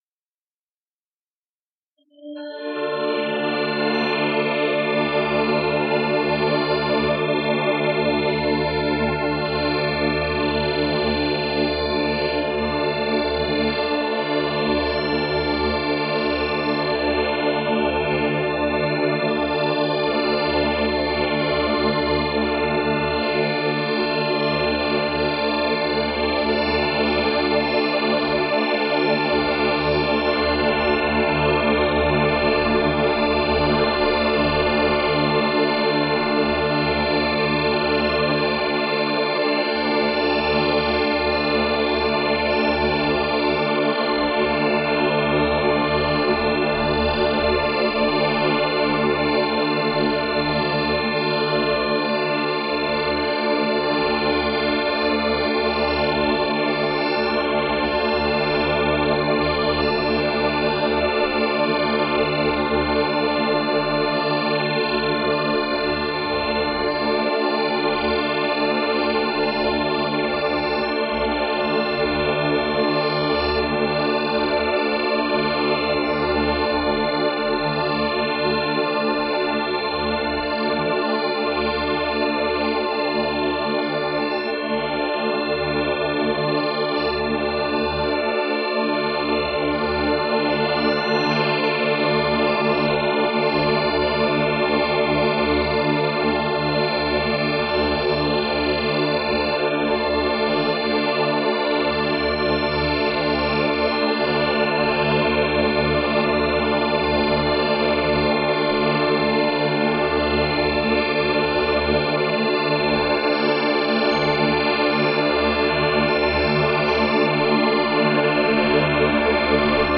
String Quartet No. 2 has been through several iterations, each with a common basis: a string quartet holding a simple chord (octaves with an added fifth) fed through a digital delay system with computer-controlled micro-inflections in pitch and harmonic spectrum.
Spectral Shadow of String Quartet No. 2 (Canon in Beta) is the latest iteration, made by running the spectrogram through the freeware image-to-sound program Coagula Light. The result is a computer-generated simulacrum of a computer-generated rendering of an imitation of a piece by Phill Niblock: yet another distorted copy, based upon the already distorted copy (itself based on a distorted copy etc.)